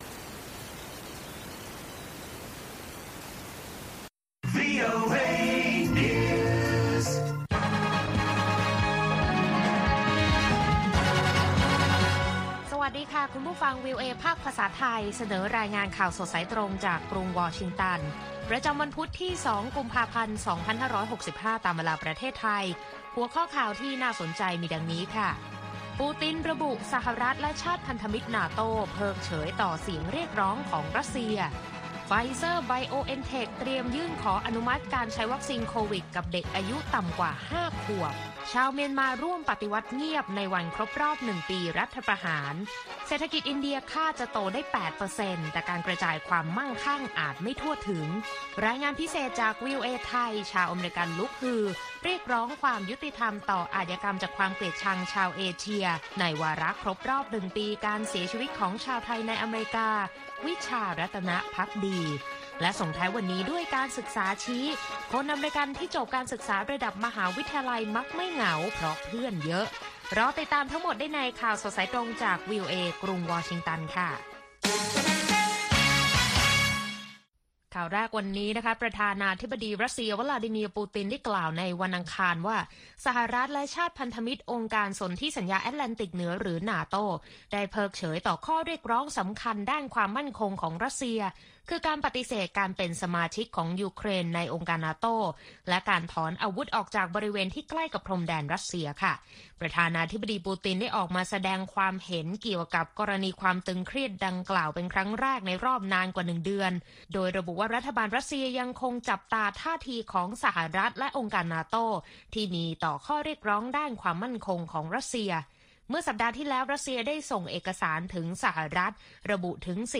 ข่าวสดสายตรงจากวีโอเอ ภาคภาษาไทย ประจำวันพุธที่ 2 กุมภาพันธ์ 2565 ตามเวลาประเทศไทย